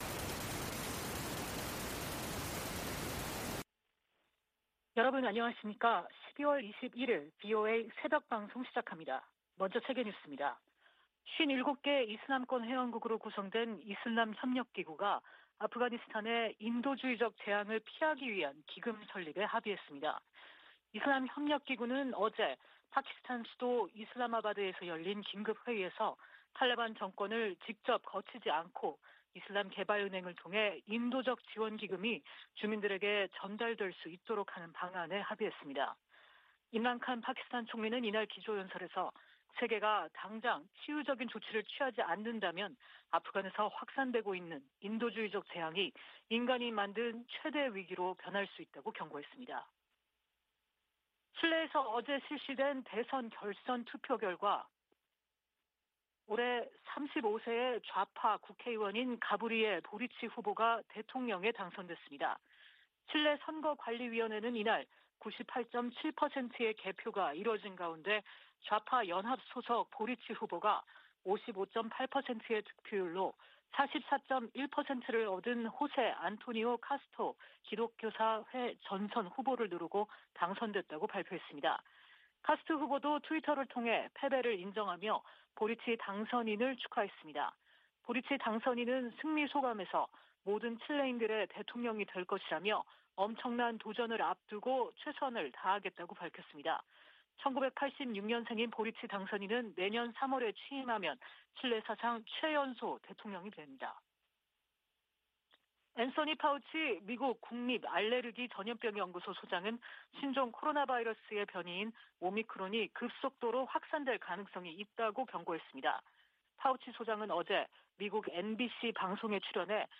VOA 한국어 '출발 뉴스 쇼', 2021년 12월 21일 방송입니다. 조 바이든 미국 행정부의 대북정책은 전임 두 행정부 정책의 중간이라는 점을 제이크 설리번 국가안보보좌관이 거듭 확인했습니다. 미 국방부는 최근 상원을 통과한 2022 회계연도 국방수권법안(NDAA)과 관련해 미한 동맹태세를 변경할 계획이 없다고 밝혔습니다. 북한이 내년 잠수함발사탄도미사일(SLBM)을 실전 배치할 것으로 예상하는 보고서가 나왔습니다.